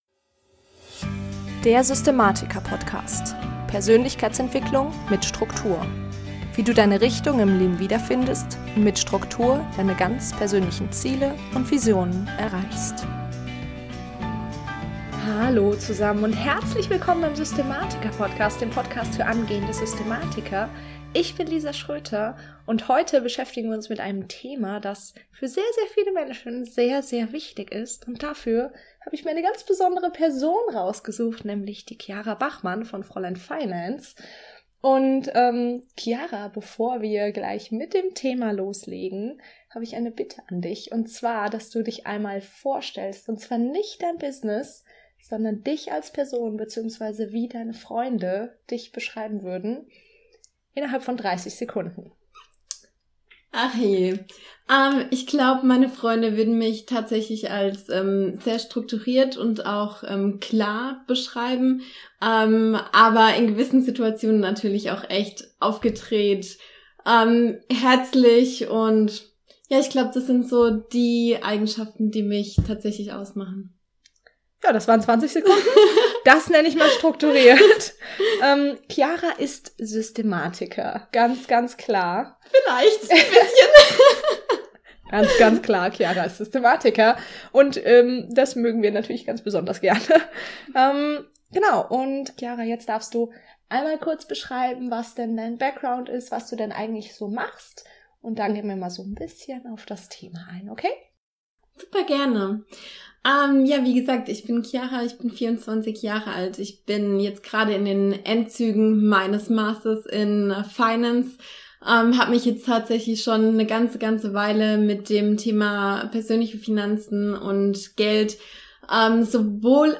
#47 – Bring Struktur in deine Finanzen! | Interview